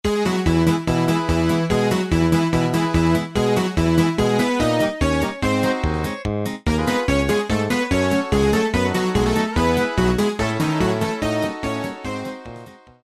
Заказ полифонической версии:
• Пример мелодии содержит искажения (писк).